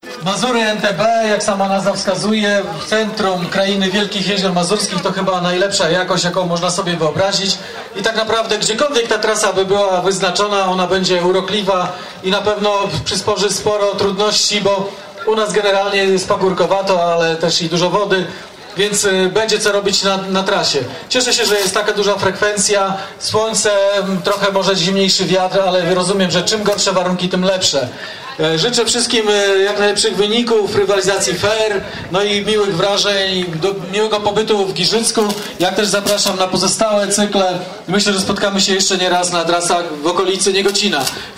Uczestników powitał burmistrz Giżycka Wojciech Iwaszkiewicz.